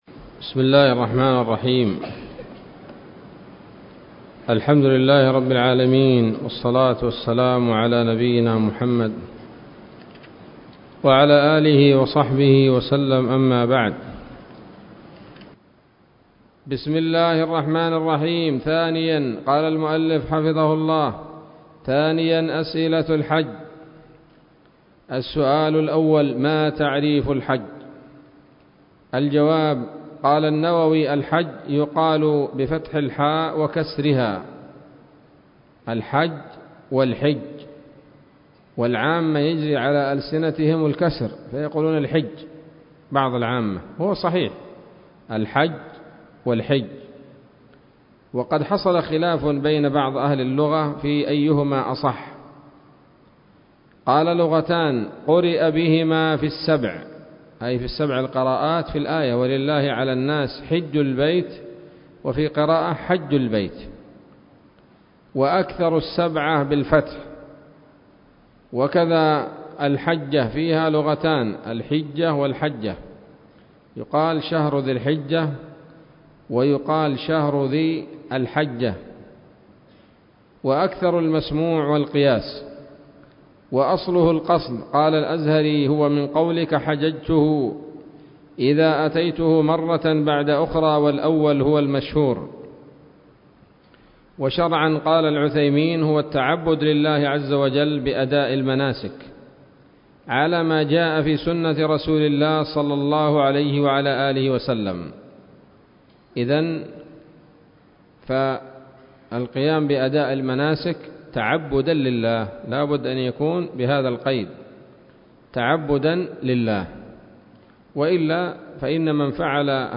الدرس الثامن عشر من شرح القول الأنيق في حج بيت الله العتيق